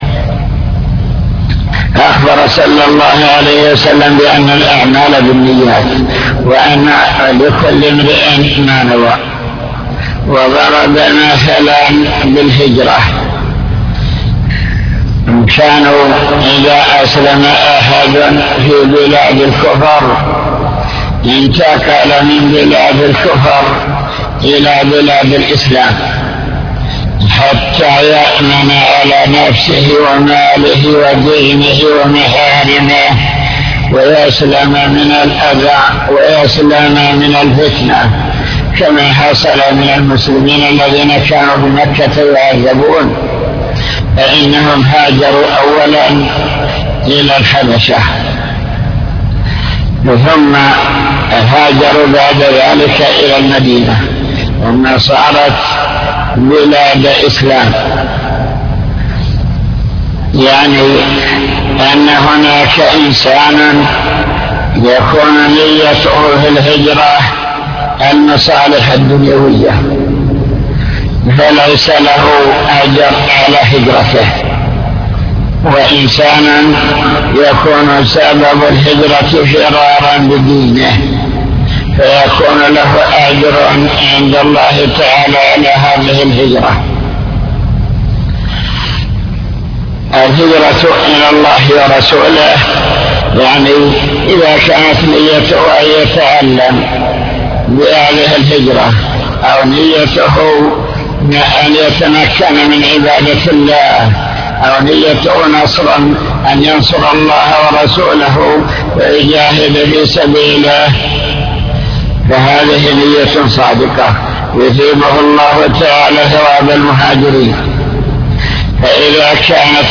المكتبة الصوتية  تسجيلات - كتب  شرح كتاب بهجة قلوب الأبرار لابن السعدي شرح حديث إنما الأعمال بالنيات